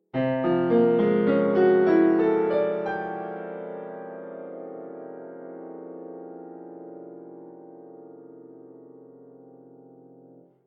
4. The next example uses quartal harmony, but, instead of stacking a series of perfect fourths on top of one another, which creates a pleasant-but-static quality, I stacked two perfect fourths, then went down by whole tone and stacked two more perfect fourths on that note, then repeated it a third time, finishing with three stacked fourths instead of two. The result is very different than just stacking fourths on top of one another until you run out of notes:
Post_Tonal_Harmony_Ex4.mp3